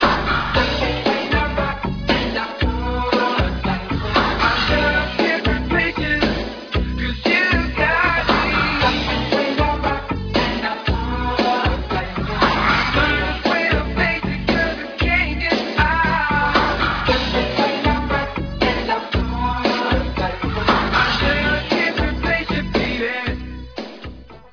background vocals and keyboards